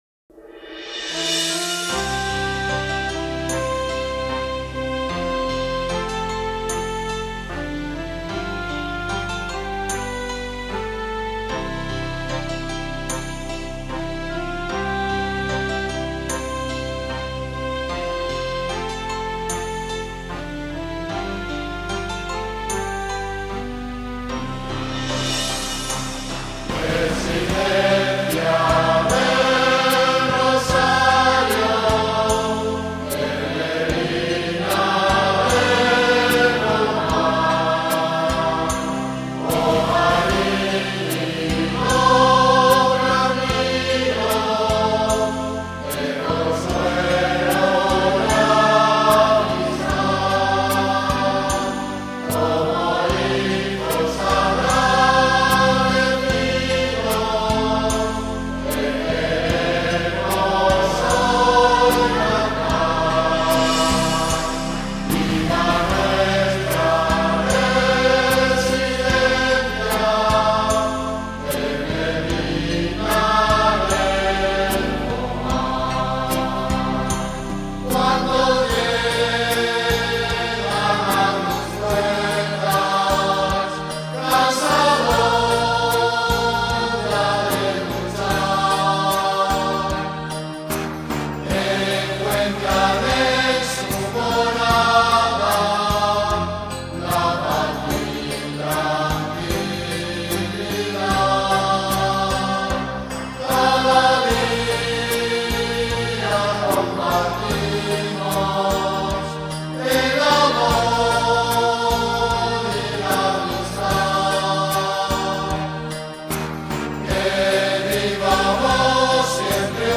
HIMNO VERSIÓN CORAL
Himno a la residencia del Rosario. Interpreta: El pueblo Canta.